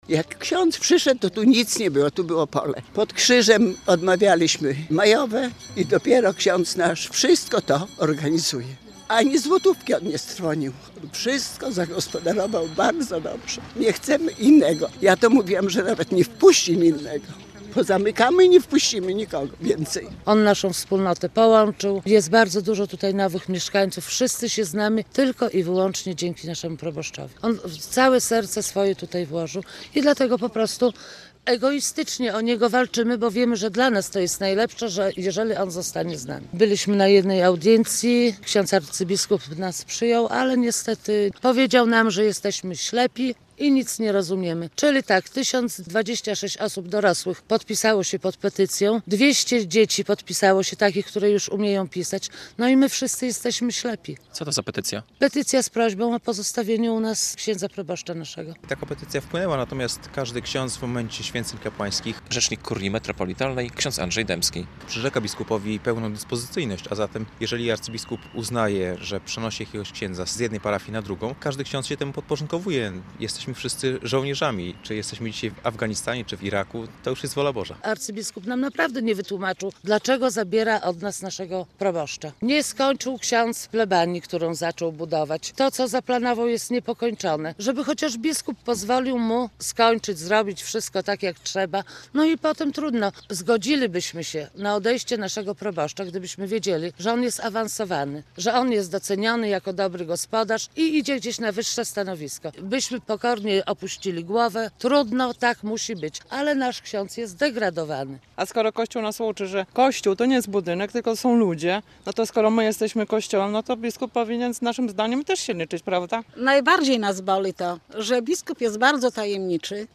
Zbuntowani parafianie - relacja